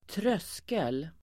Uttal: [²tr'ös:kel]